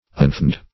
Unfeigned \Un*feigned"\, a.